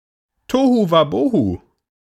Ääntäminen
Synonyymit pandemonium turmoil Ääntäminen US Tuntematon aksentti: IPA : /ˈkeɪ.ɒs/ IPA : /ˈkeɪ.ɑs/ Haettu sana löytyi näillä lähdekielillä: englanti Käännös Ääninäyte Substantiivit 1.